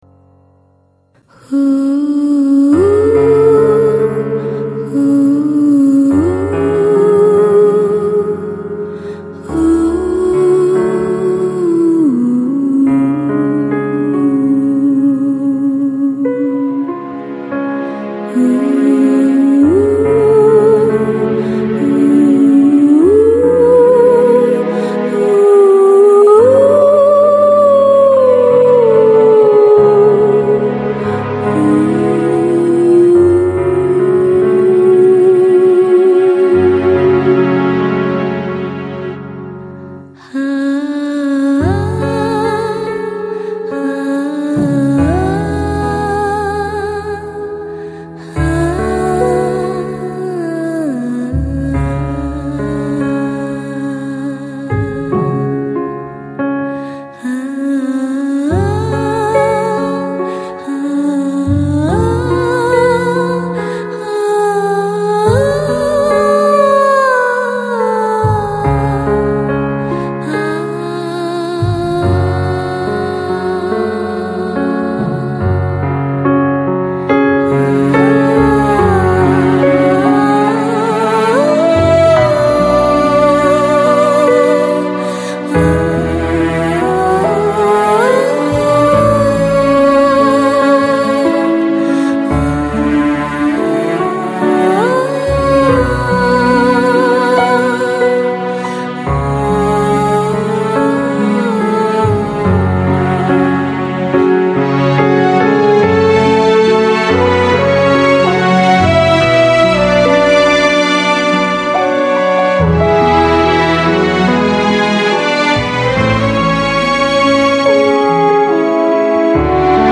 一首未知忧伤哼唱，凄美而迷人的旋律 激动社区，陪你一起慢慢变老！